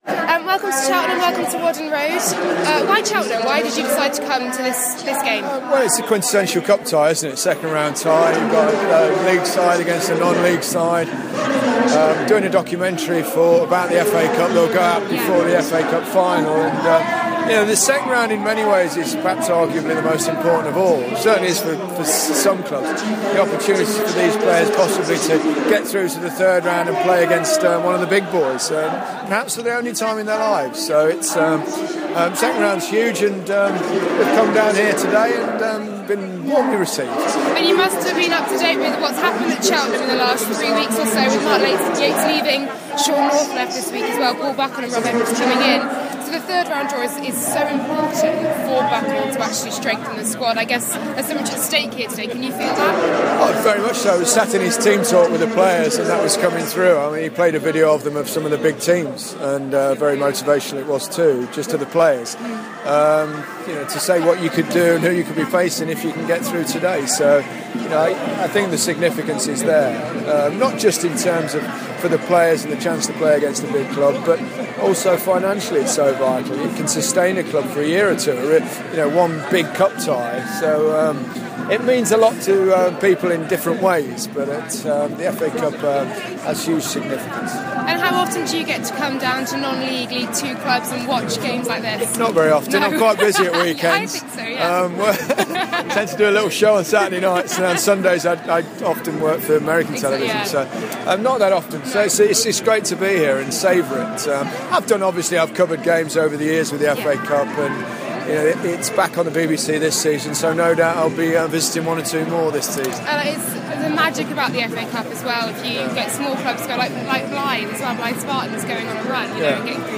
Gary Lineker at Whaddon Road
Gary Lineker spoke to the Gloucestershire Echo before Cheltenham Town's FA Cup second-round clash with Dover Athletic.